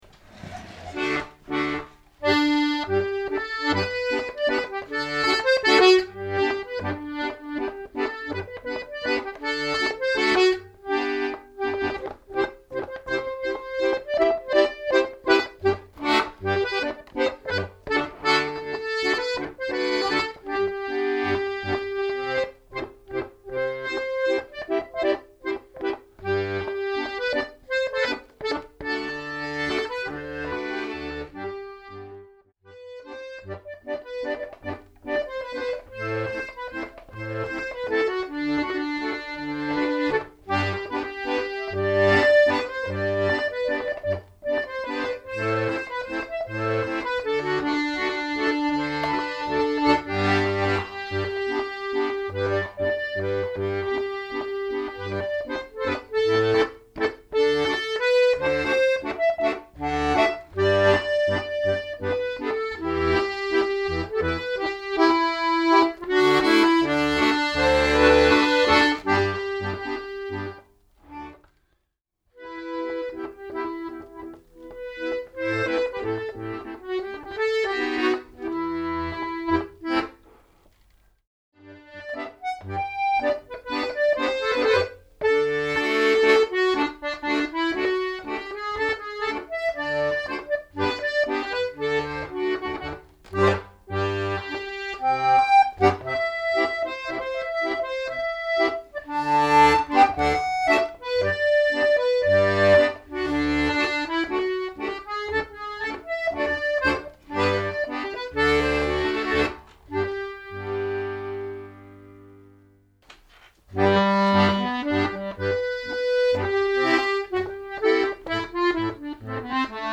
PPS. Tässä pientä kaksirivistä haitaria äänitettynä RM700-parilla (blumlein-tyyliin) parin metrin päästä. Mikkiparin suuntaus ei välttämättä ole ihan kohdallaan.
Tila on luokkaa 6x6 m2 olohuone, enimmät seinät kirjahyllyjä täynnä. Hmm... tuossa äänityksessä ei kyllä muuten ole kaikki kohdallaan. Mutta tuo mistä haitarin eri päät kuuluvat pitäisi olla suurin piirtein kohdallaan.